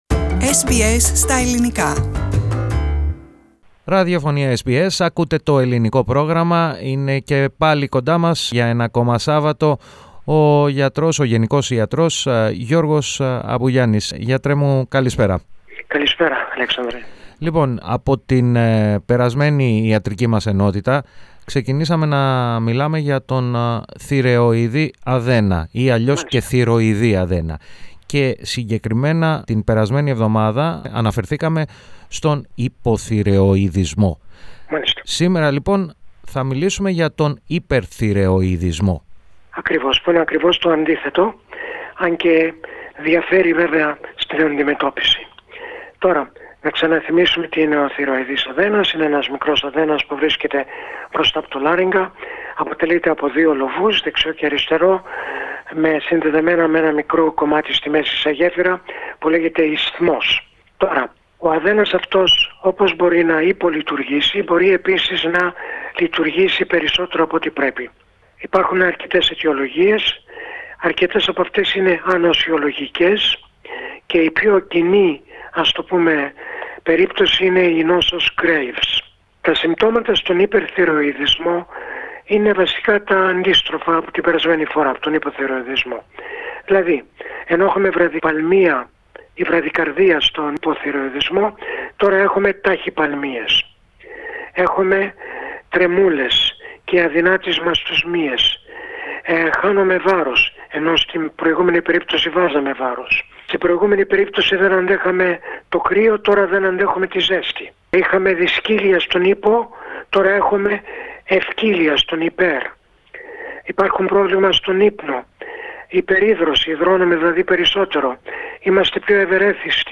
γενικό γιατρό